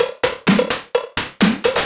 Index of /VEE/VEE Electro Loops 128 BPM
VEE Electro Loop 419.wav